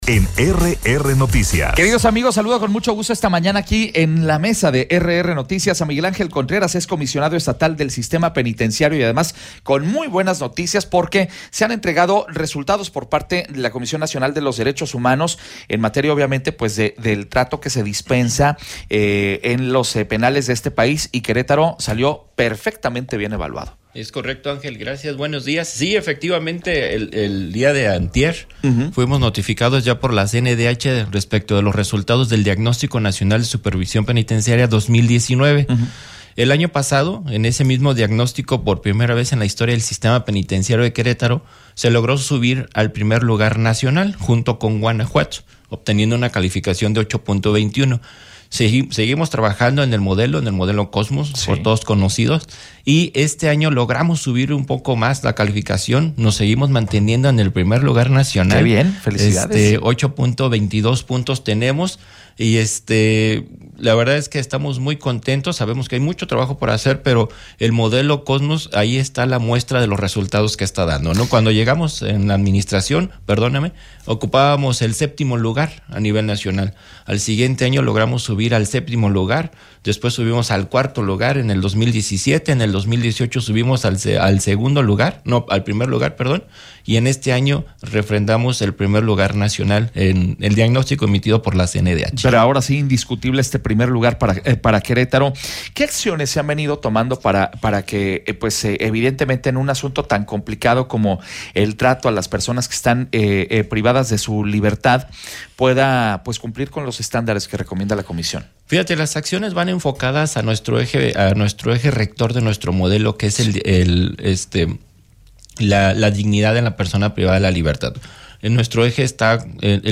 ENTREVISTA-MIGUEL-ANGEL-CONTRERAS-COMISIONADO-ESTATAL-DEL-SISTEMA-PENITENCIARIO.mp3